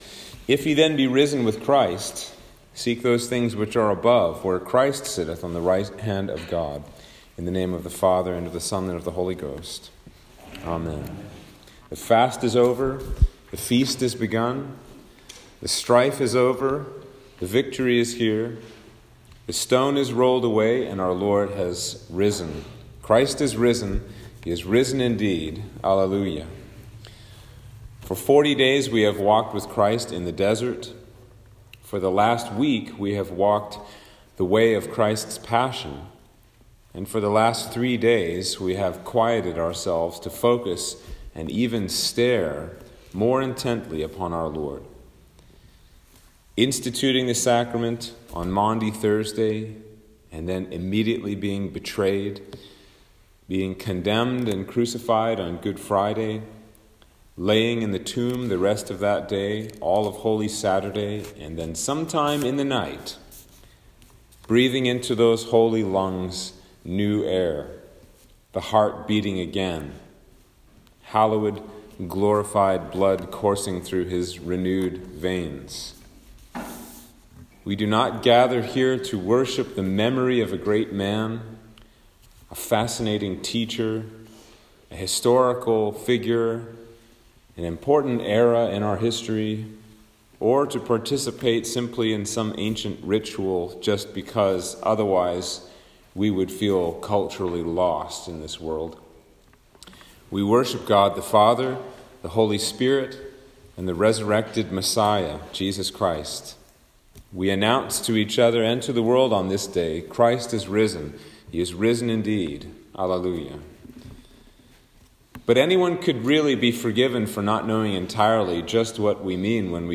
Sermon for Easter